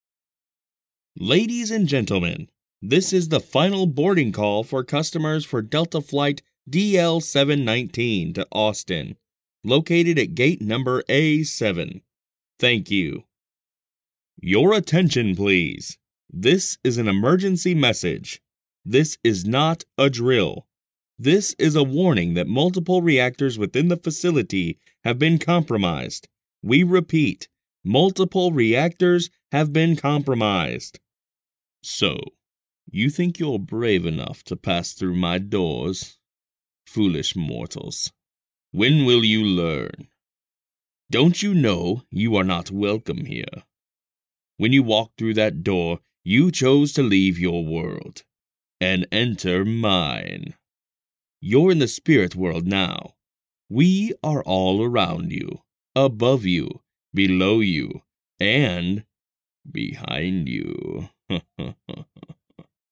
I have worked with some great clients such as WalMart, SNHU, Oil Changers INC, ETC. I bring professional Studio quality sound to you project.
Announcer demo
I specialize in strong, deep, guy next door, conversational, confident, friendly, and professional voice overs. i can provide a free sample if needed.
My experiences in vo include: • e learning • commercial • radio • tv • internet video • telephony • character • audio book • narration I use a rode nt microphone microsoft computer adobe audition daw.
announcer demo.mp3